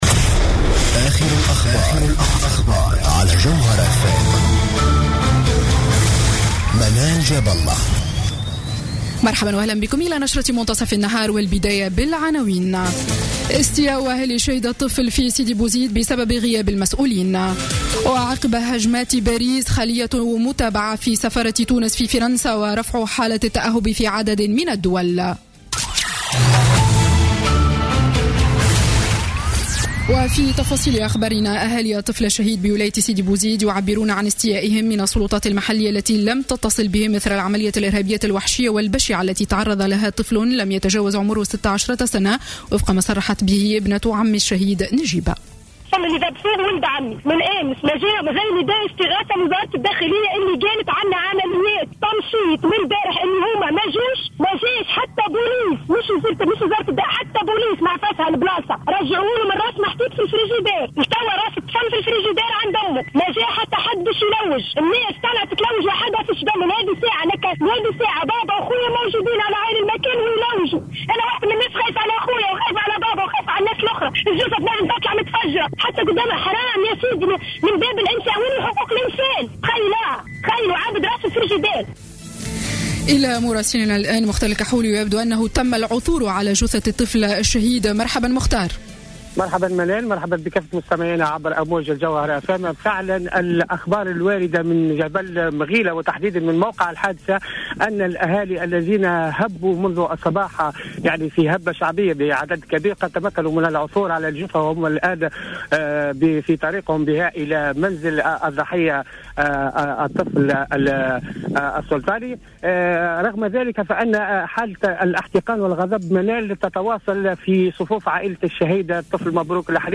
نشرة أخبار منتصف النهار ليوم السبت 14 نوفمبر 2015